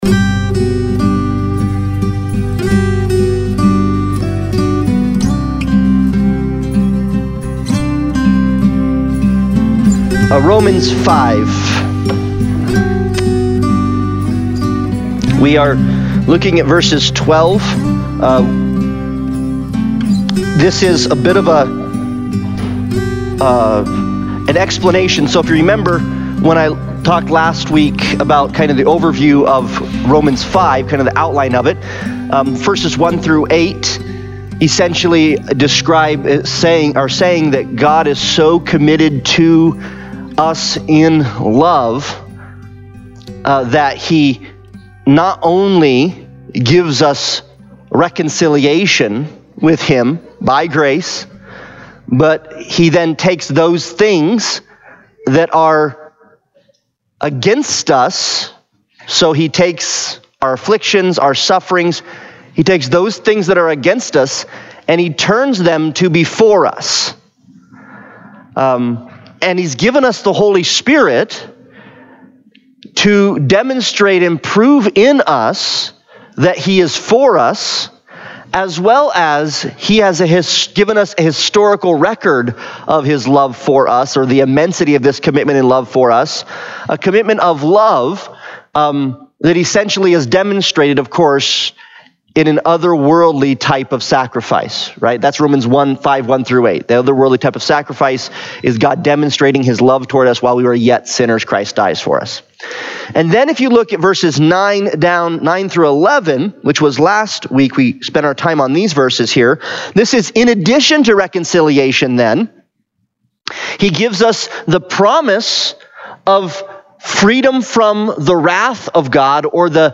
Romans Analysis Passage: Romans 5:12-20 Service Type: Sunday Bible Study « Sing